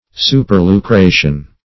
Search Result for " superlucration" : The Collaborative International Dictionary of English v.0.48: Superlucration \Su`per*lu*cra"tion\, n. [Pref. super- + L. lucratio gain.] Excessive or extraordinary gain.